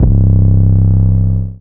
MZ 808 [Gucci].wav